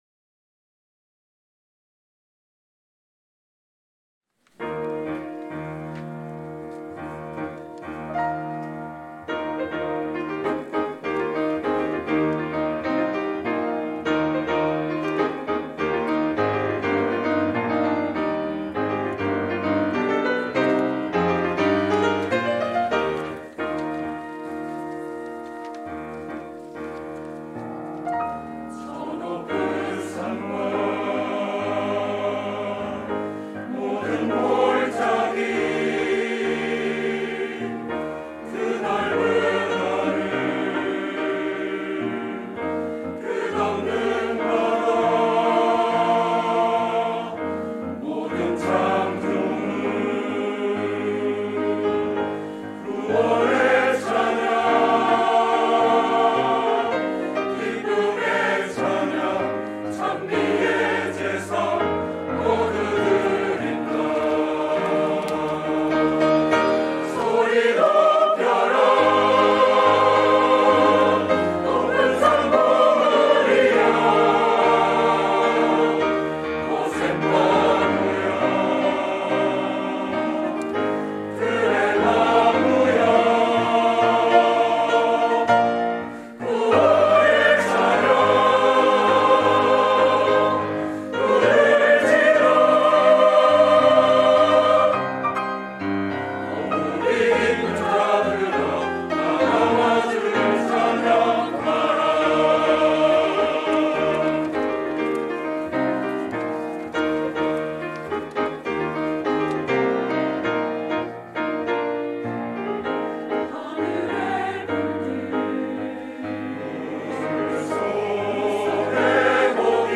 찬양의 소리